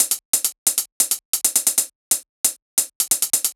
Cl Hat Loop 135bpm.wav